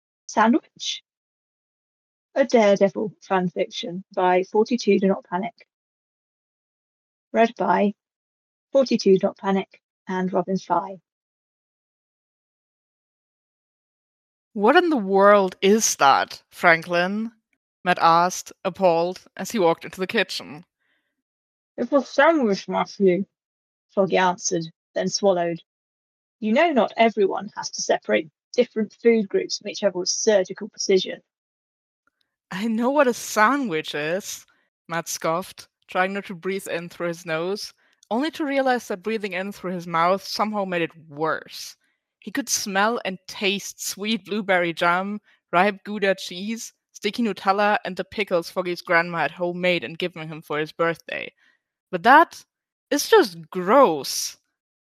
collaboration|two voices